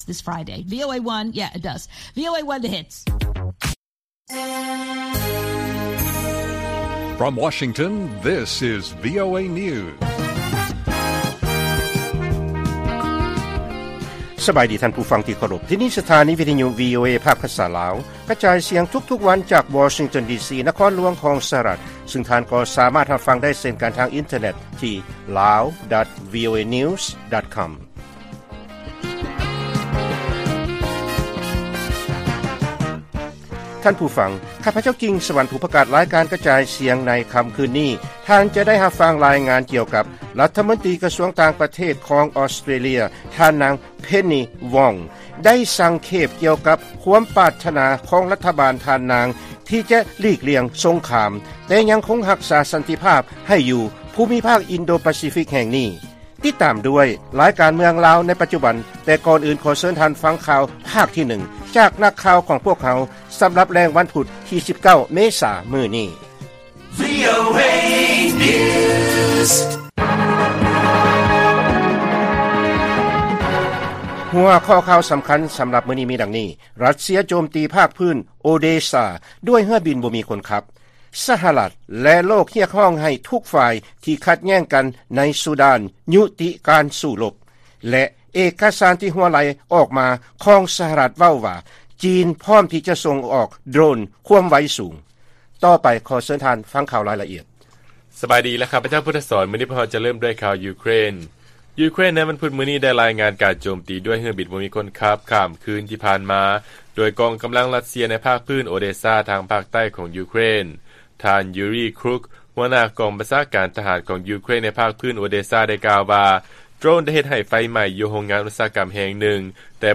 ລາຍການກະຈາຍສຽງຂອງວີໂອເອ ລາວ: ຣັດເຊຍ ໂຈມຕີພາກພື້ນ ໂອເດຊາ ດ້ວຍເຮືອບິນບໍ່ມີຄົນຂັບ